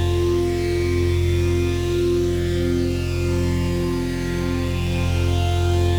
Index of /musicradar/dystopian-drone-samples/Non Tempo Loops
DD_LoopDrone4-F.wav